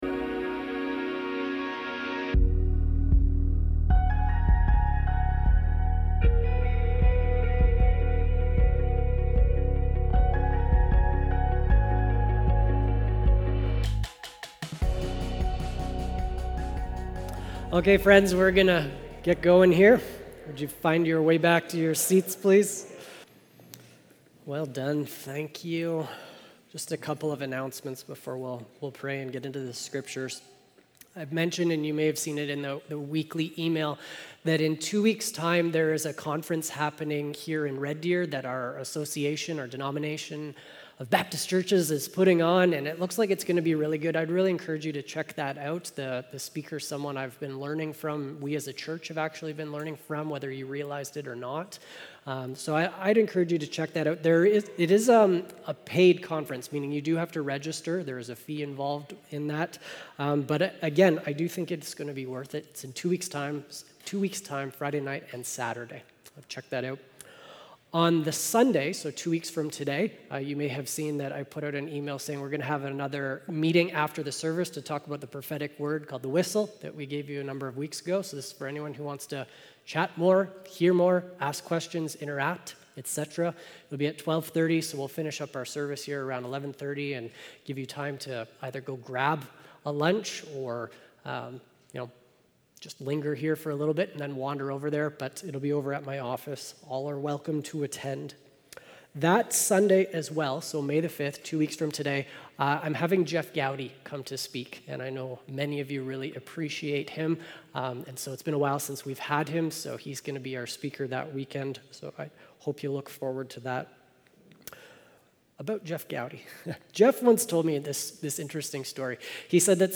Sermons | Unity Baptist Church
Current Sermon